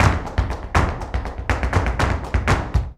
Index of /90_sSampleCDs/Best Service ProSamples vol.46 - Flamenco [AKAI] 1CD/Partition C/BULERIAS TAP